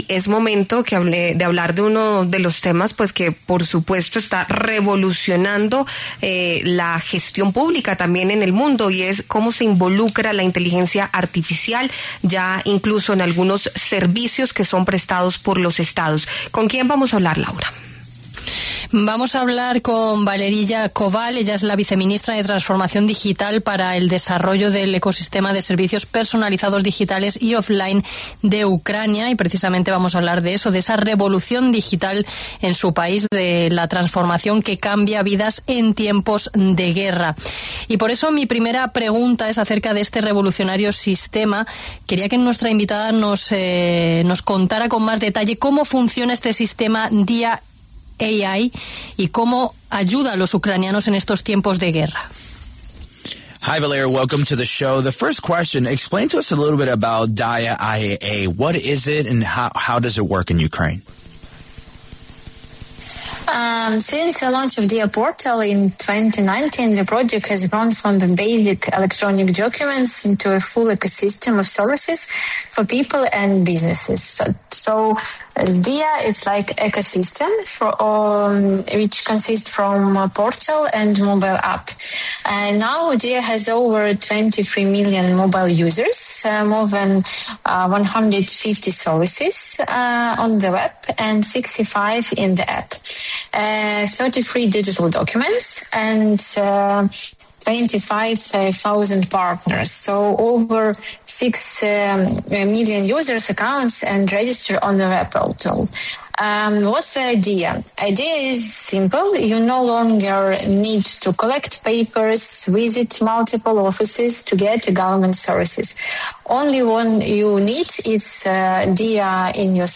Este lunes, 13 de octubre, Valeriya Koval, viceministra de transformación digital para el desarrollo del ecosistema de servicios personalizados digitales y offline de Ucrania, pasó por los micrófonos de La W Radio y dio detalles del programa ‘Diia AI’.